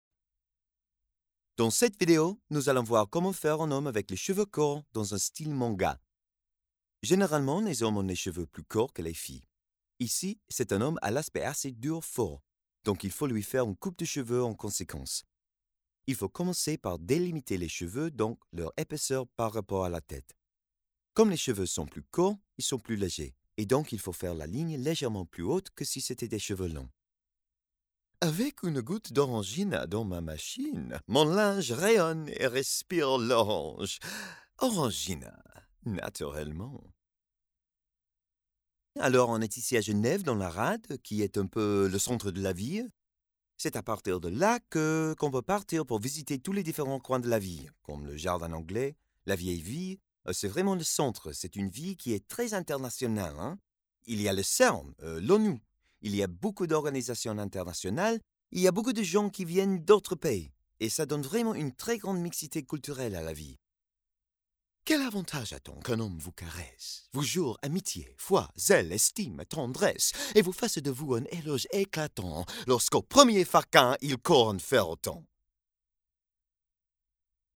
Démo commerciale
Microphone à valve à condensateur cardioïde Sontronics Aria
Microphone dynamique SHURE SM7B